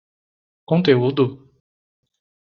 Pronunciado como (IPA)
/kõ.teˈu.du/